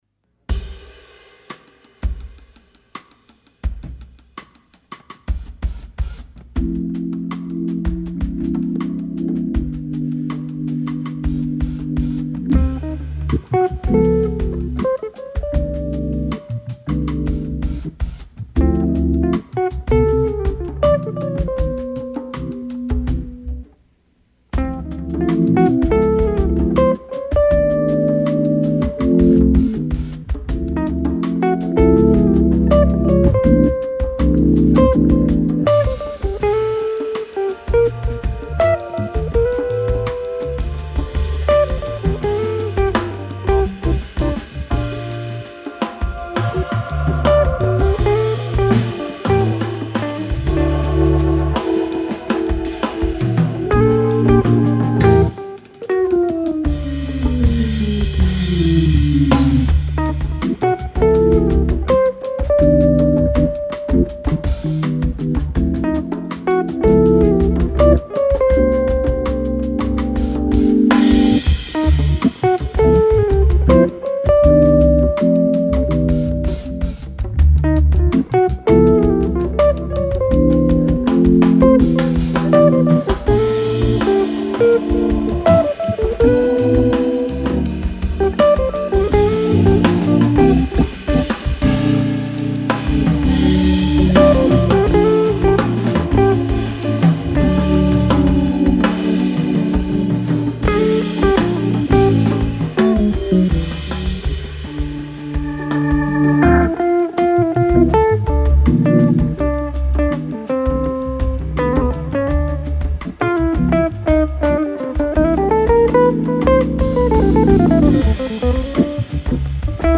FILE: Jazz